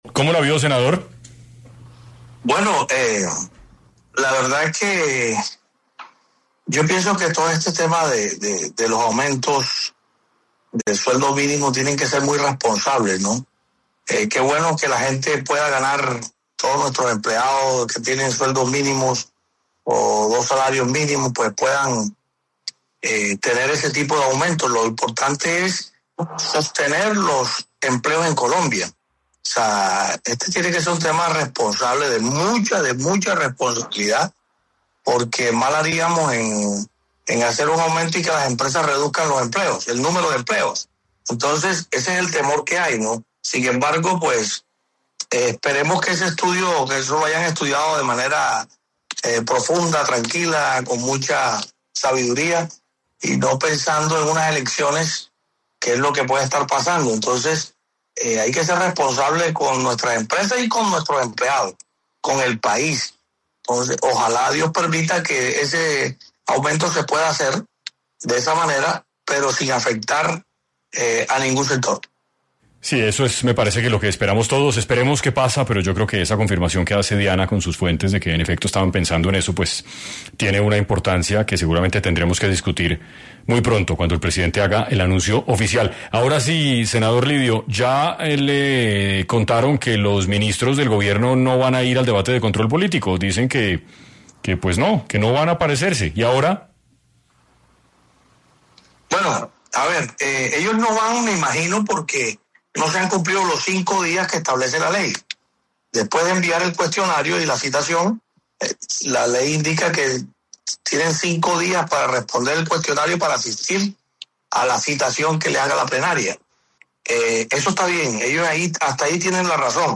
Es así que, en 6 AM de Caracol Radio, habló el presidente del Senado, Lidio García, quien aseguró que, el Congreso, debe darle la cara a los colombianos frente a estas medidas económicas tomadas por el gobierno Petro.